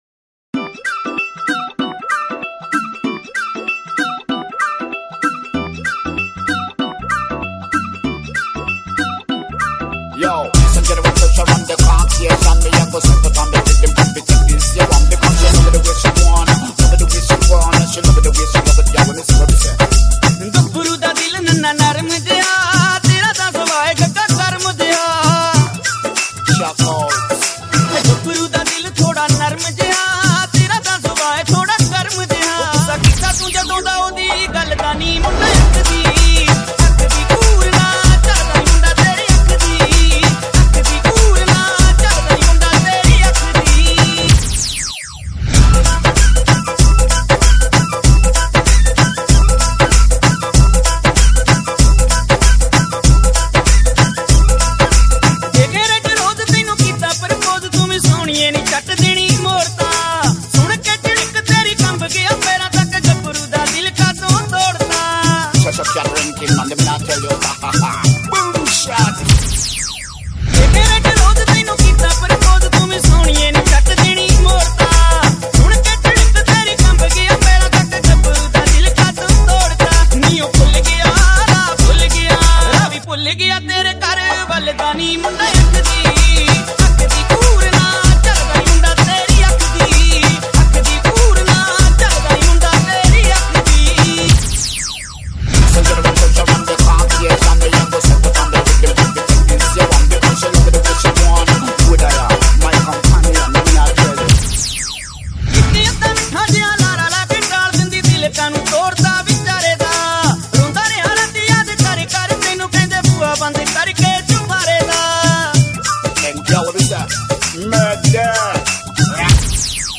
Category: UK Punjabi
Remix